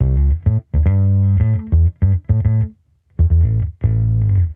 Index of /musicradar/sampled-funk-soul-samples/105bpm/Bass
SSF_PBassProc2_105A.wav